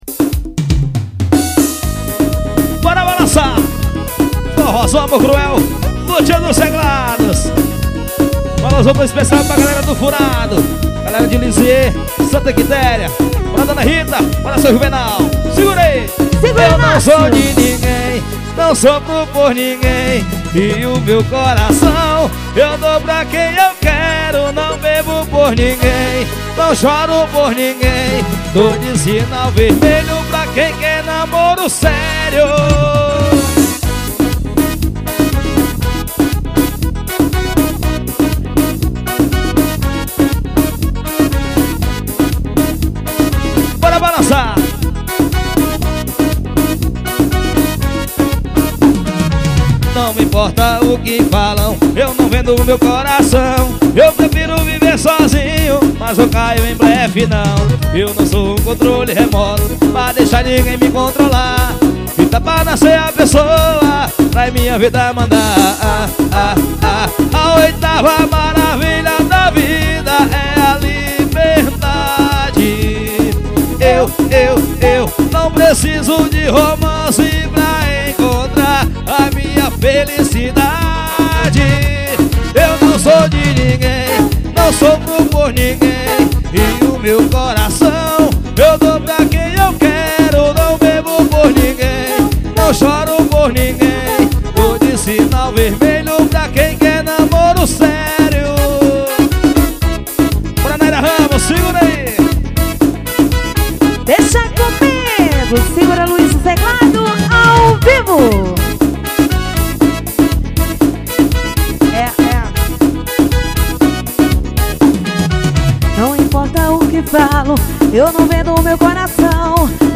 forró estourado.